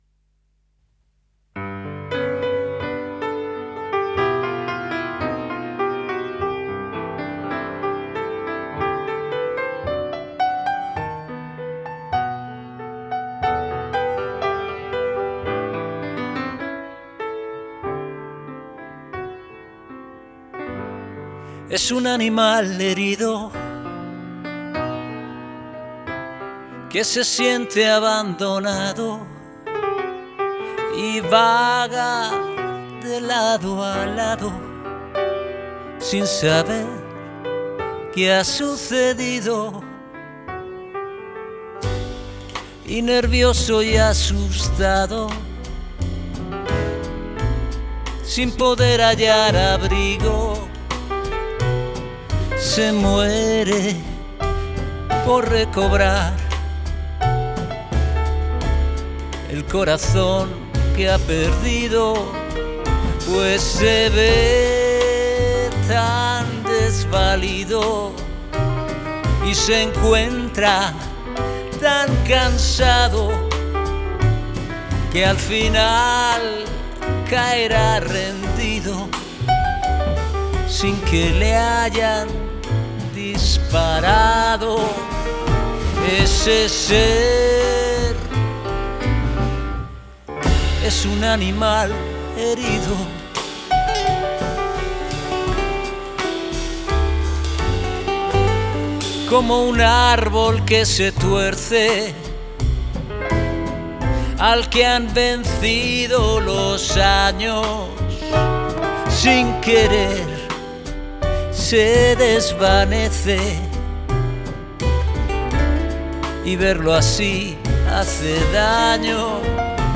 Animal herido es una canción que compuse hace algún tiempo. Con la ayuda de Federico Lechner la hemos convertido en un bolero que salta al tango a mitad de la canción. Todo eso con una atmosfera de jazz y con la espectacular armónica de Antonio Serrano.